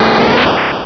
pokeemerald / sound / direct_sound_samples / cries / kakuna.aif